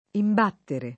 vai all'elenco alfabetico delle voci ingrandisci il carattere 100% rimpicciolisci il carattere stampa invia tramite posta elettronica codividi su Facebook imbattere [ imb # ttere ] v.; imbatto [ imb # tto ] — coniug. come battere